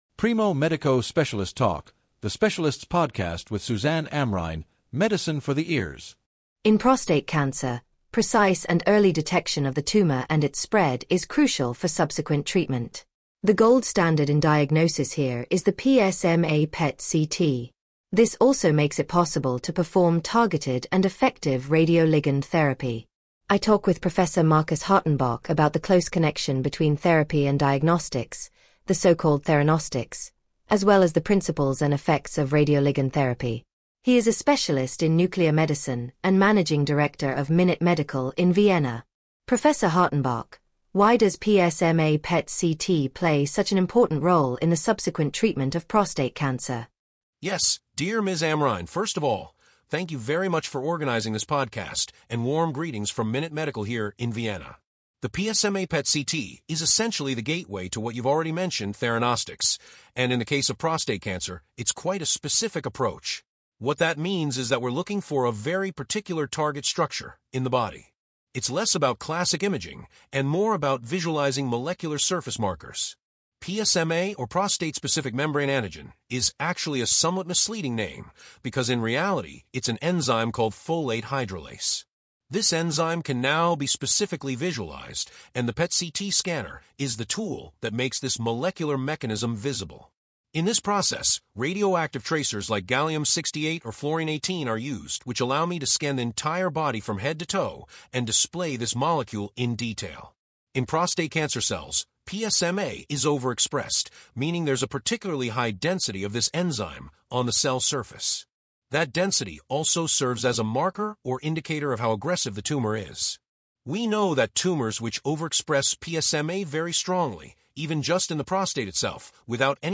Interview
conducted by Primo Medico on 31 March 2025 (English audio AI-generated, original in German)